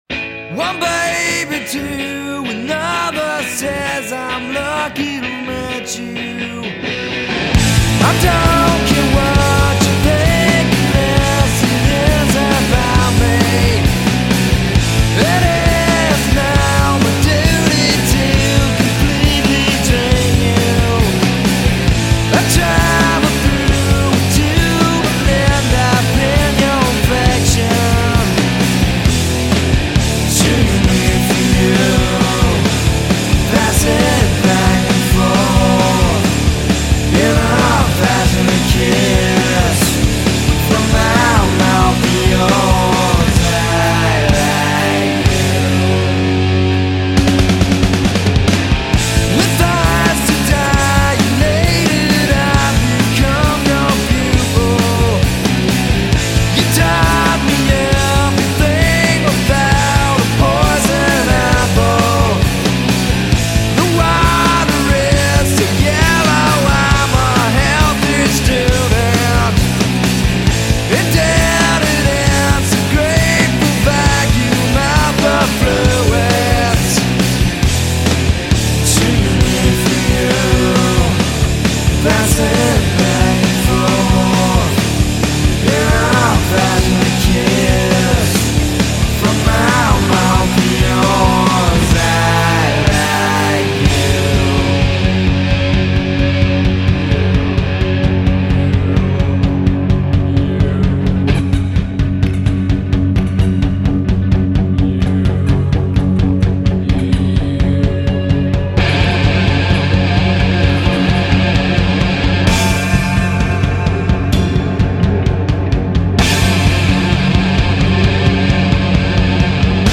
Grunge Rock